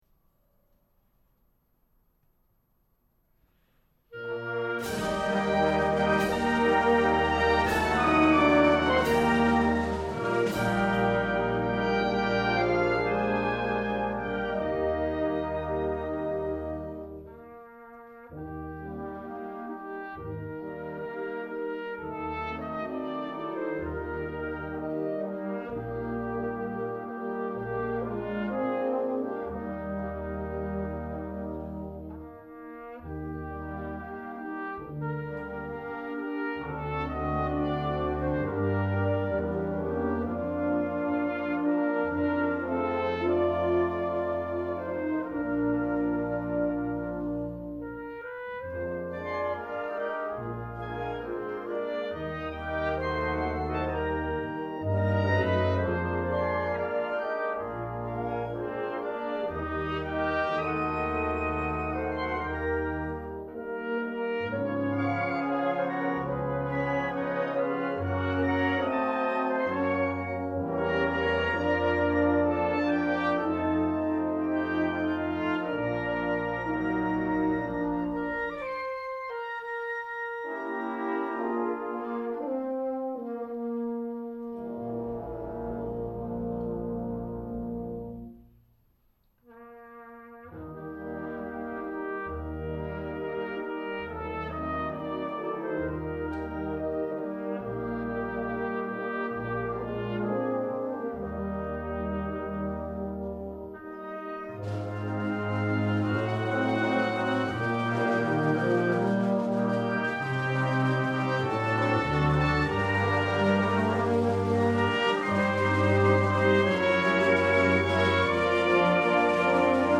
These were sight read and recorded on August 7'th 2007.